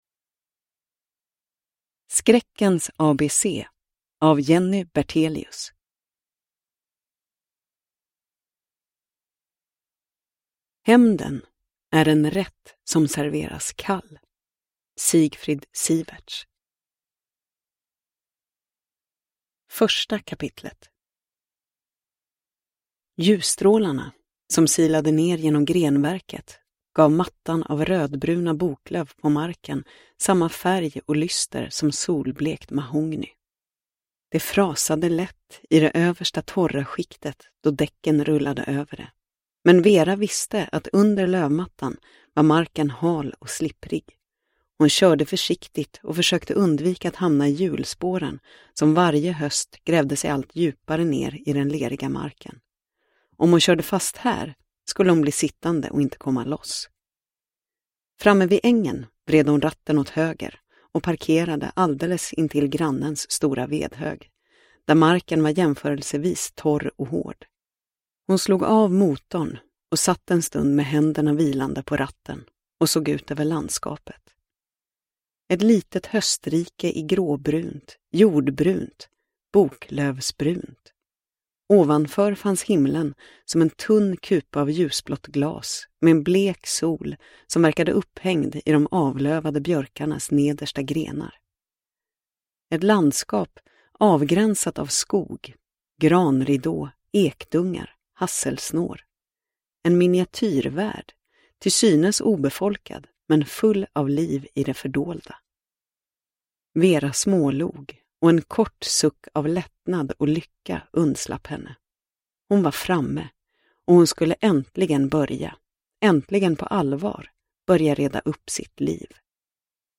Skräckens ABC – Ljudbok – Laddas ner